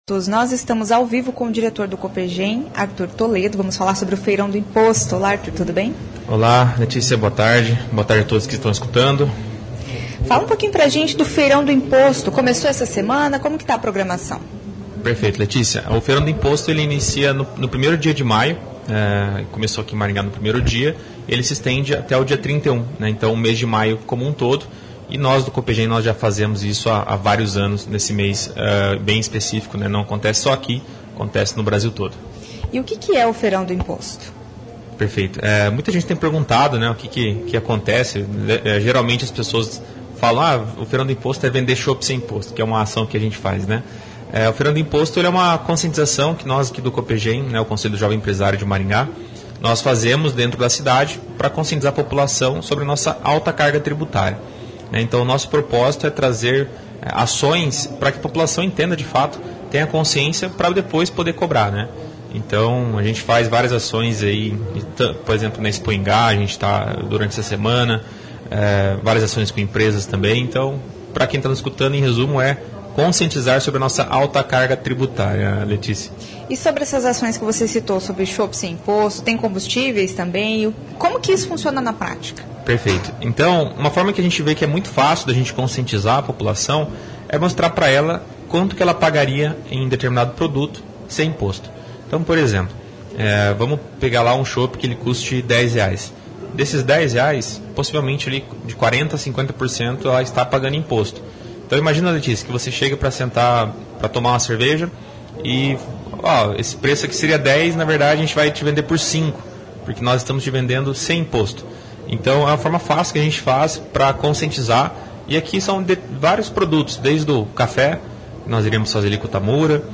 Entre as ações do Feirão do Imposto, estão a comercialização de produtos como chope e combustível sem a cobrança dos impostos. Ouça a entrevista.